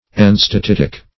Enstatitic \En`sta*tit"ic\, a. Relating to enstatite.